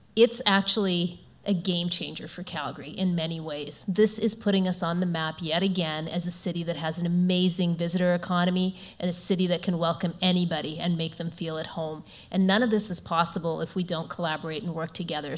Major Joyti Gondek shares how the Grey Cup will affect the city long term.